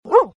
Download Dogs sound effect for free.
Dogs